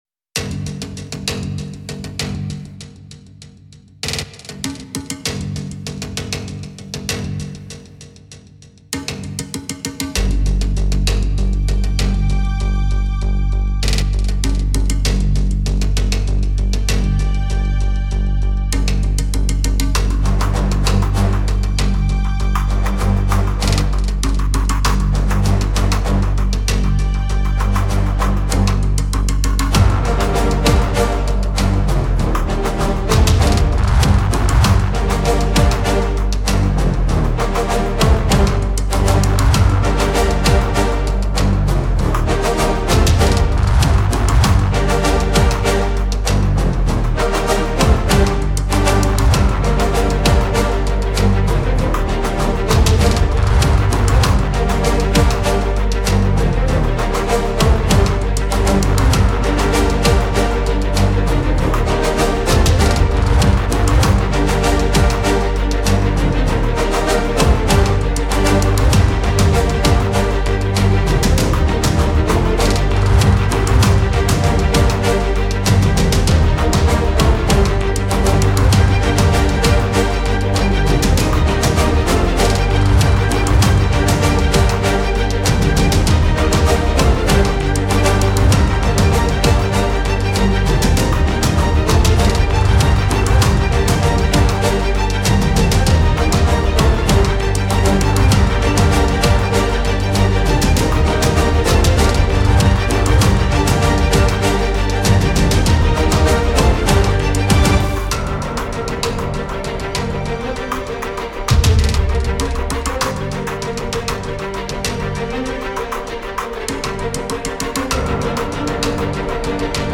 Genre: filmscore, trailer.